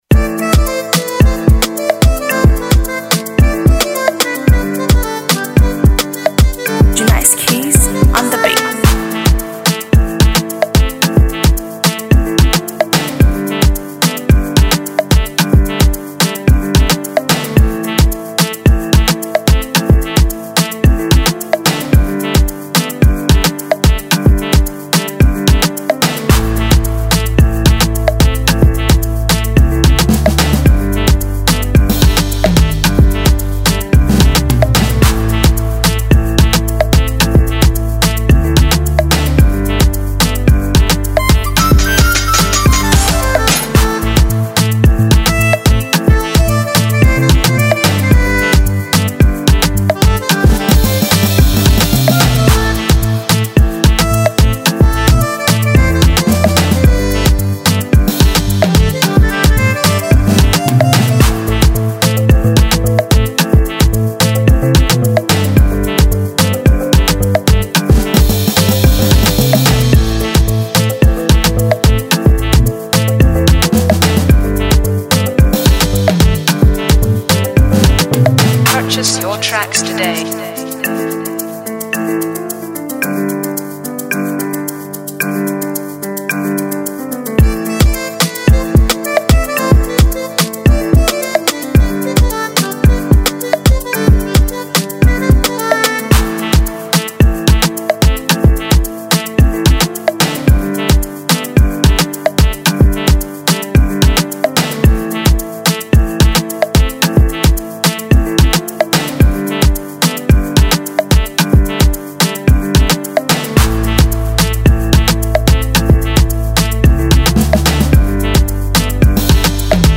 2021-08-30 1 Instrumentals 0
FREE BEATS